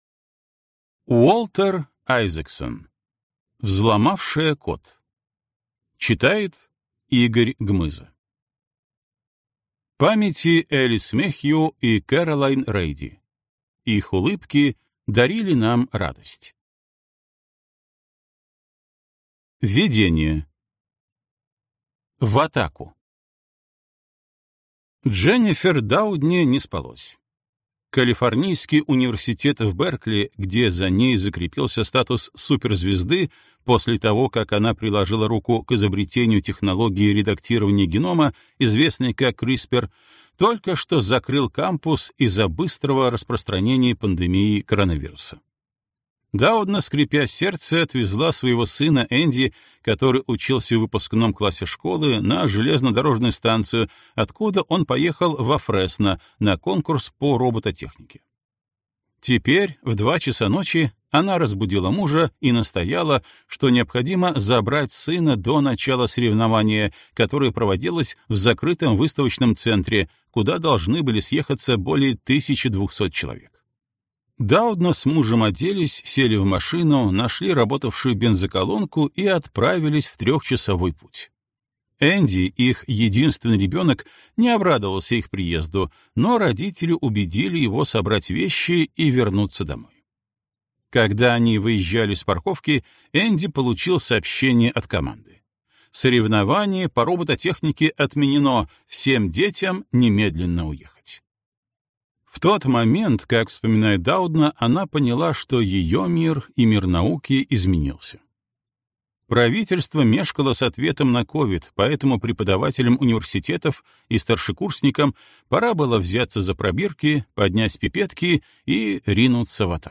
Аудиокнига Взломавшая код. Дженнифер Даудна, редактирование генома и будущее человечества | Библиотека аудиокниг